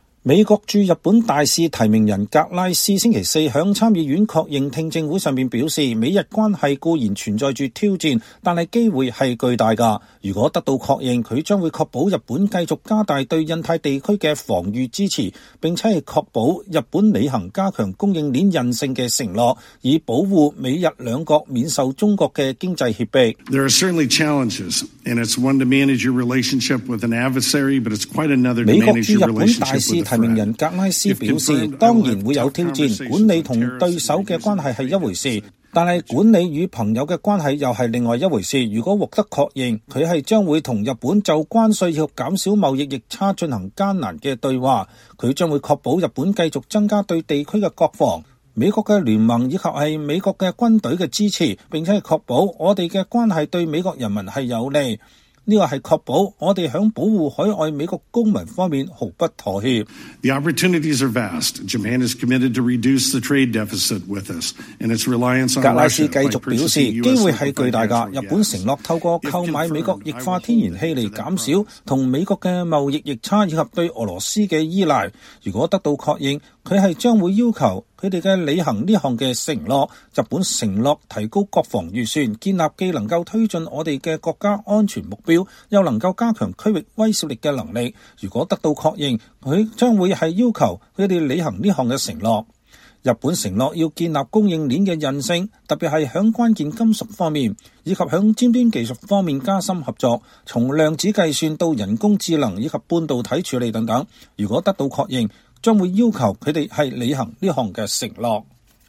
美國駐日本大使提名人格拉斯(George Edward Glass)星期四在參議院確認聽證會上說，美日關係固然存在挑戰，但機會是巨大的。如果得到確認，他將確保日本繼續加大對印太地區的防禦支持，並確保日本履行加強供應鏈韌性的承諾，以保護美日兩國免受中國的經濟脅迫。